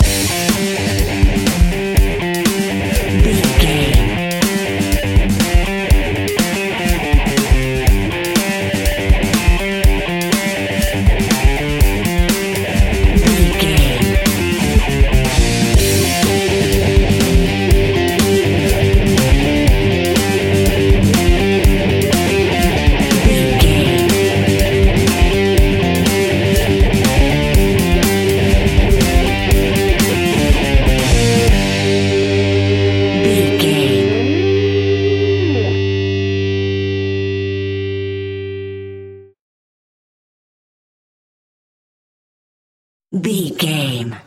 Epic / Action
Aeolian/Minor
hard rock
blues rock
Rock Bass
heavy drums
distorted guitars
hammond organ